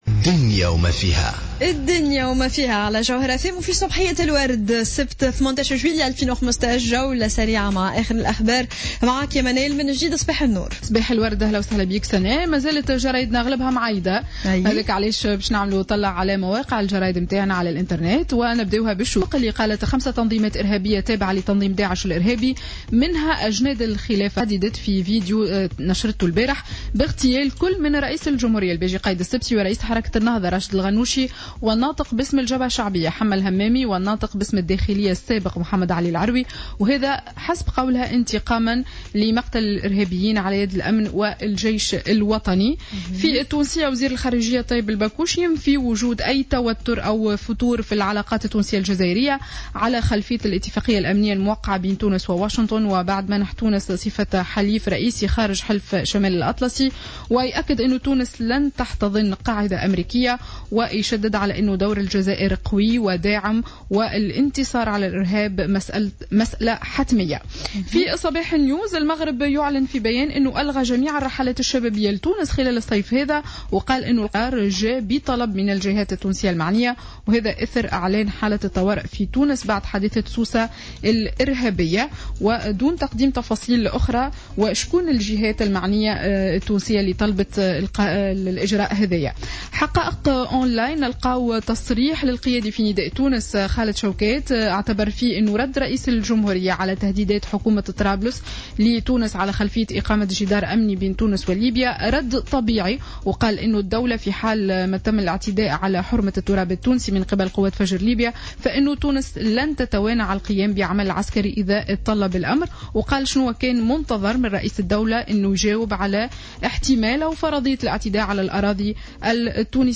Revue de presse du samedi 18 Juillet 2015